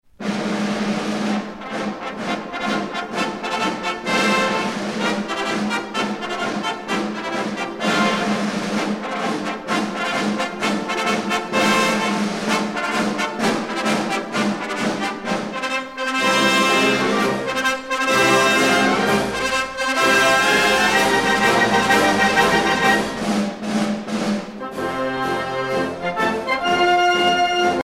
à marcher
militaire
Pièce musicale éditée